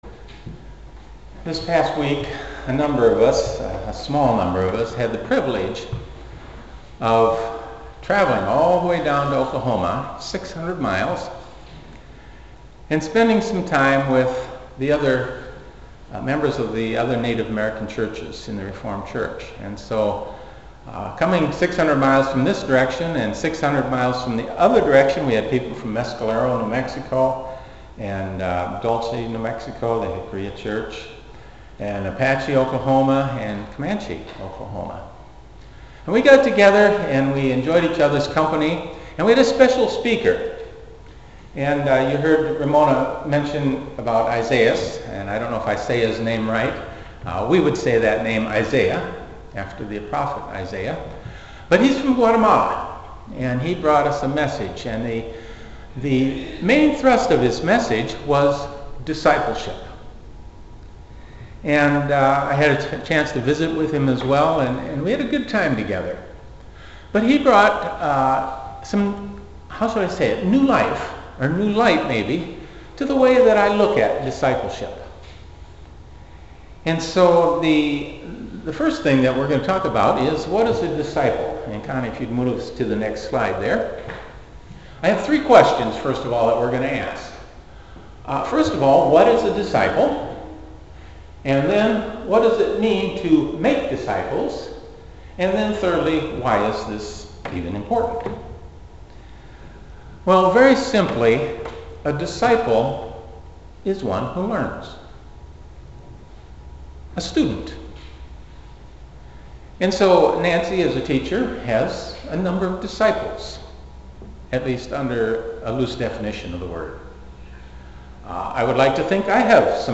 Audio recordings of sermons presented to the Winnnebago Reformed Church
Sermon Recordings